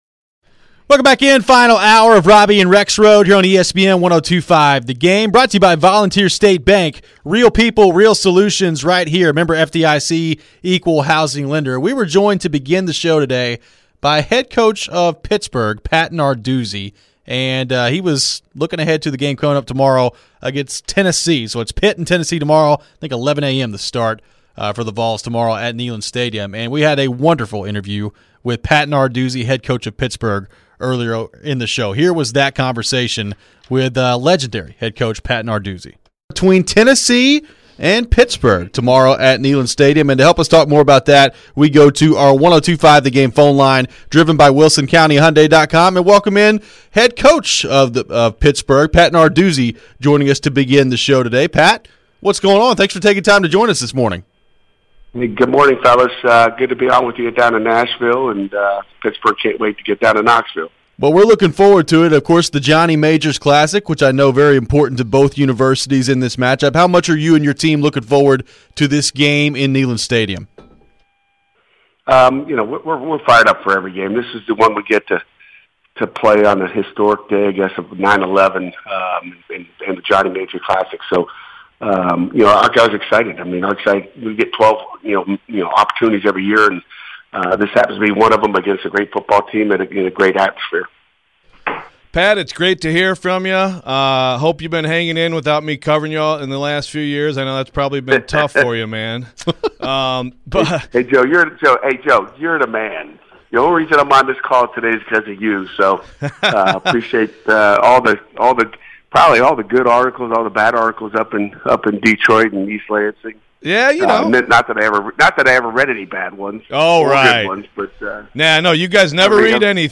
In the final hour of the show we replay an interview from the beginning of the show with Pitt football coach Pat Narduzzi. We get his thoughts on the Pitt Tennessee match-up this weekend and changes around the college football landscape.